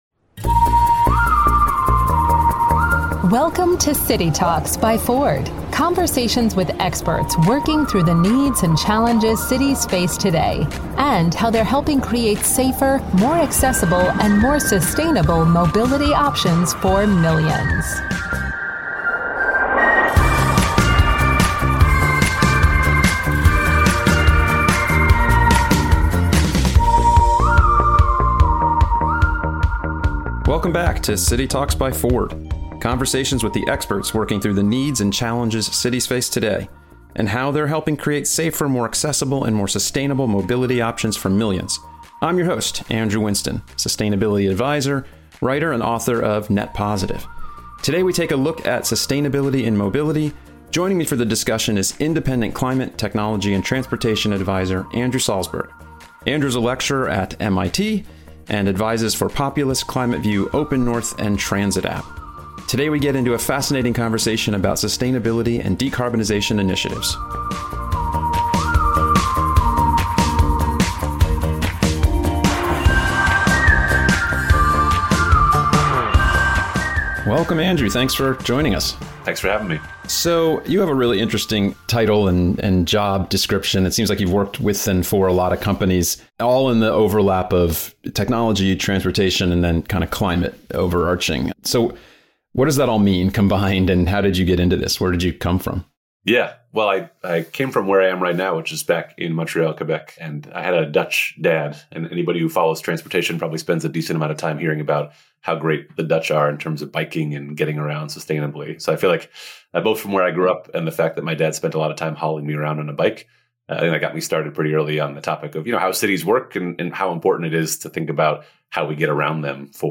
Today the duo are diving into a fascinating conversation about sustainability and decarbonization initiatives. Learn first hand about the current state of sustainability for mobility in our cities and why the electrification of transportation is so important to meet sustainability goals in our communities.